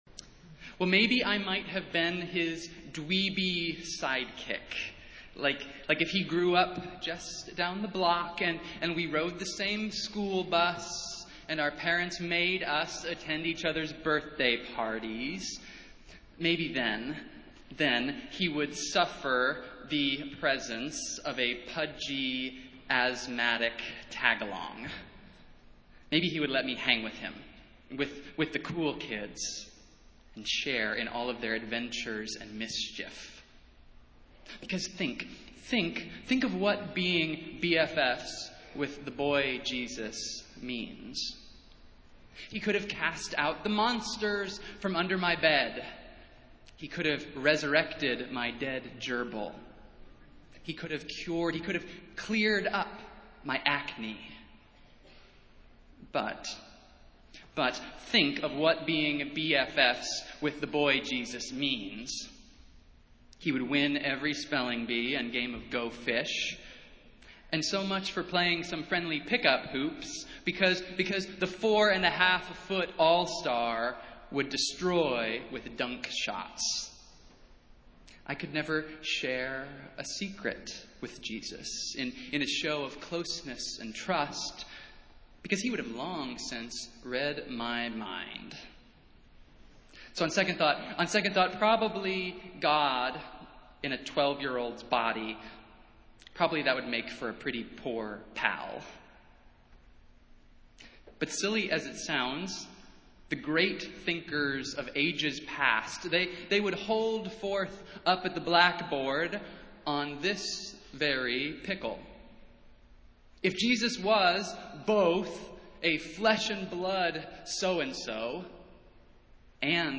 Festival Worship - Vision Sunday